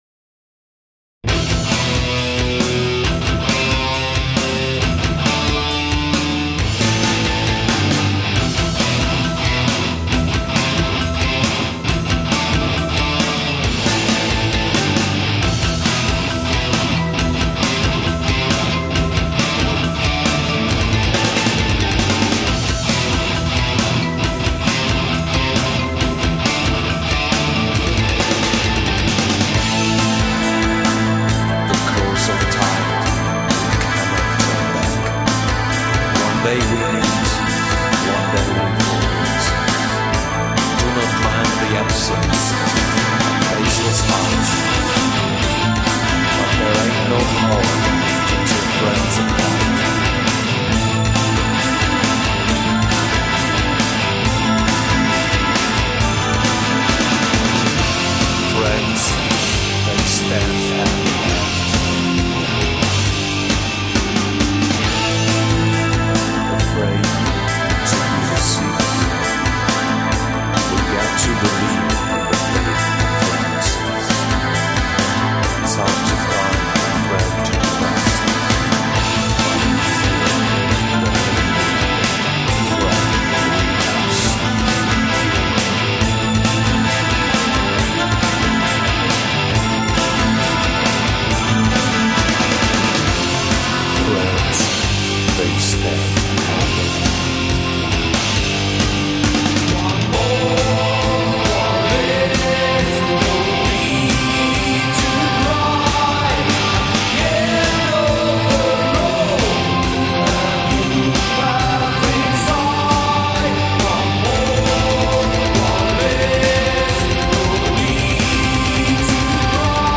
Delta Studio, Wilster (Deutschland)
Gesang
Gitarre, Bass, Keyboards
Schlagzeug